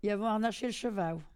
Maraîchin
Catégorie Locution